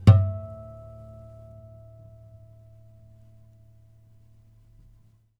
strings_harmonics
harmonic-09.wav